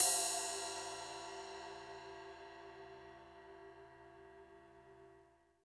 Index of /90_sSampleCDs/Roland - Rhythm Section/CYM_Rides 1/CYM_Ride menu